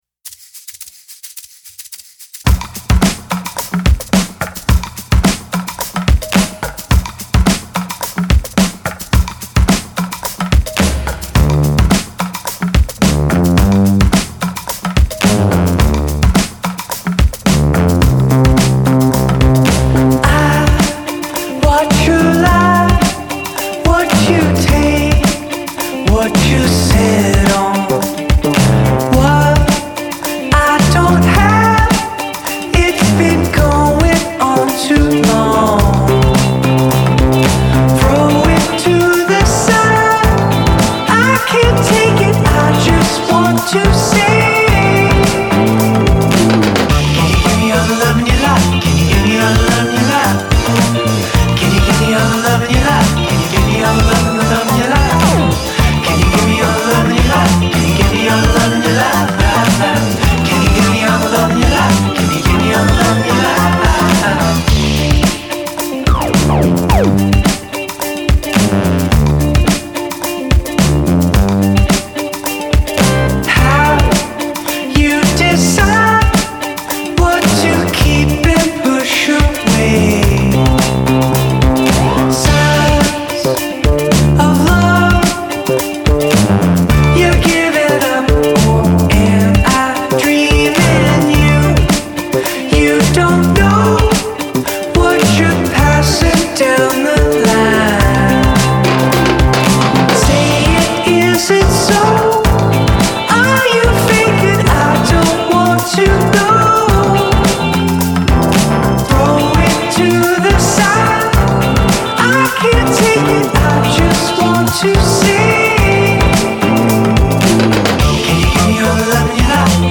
sublime summertime groove